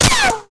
ns/releases/3.2.0/sound/weapons/ric4.wav at e83f9ea54690ccb400a546d6c9d8c6c0383b305d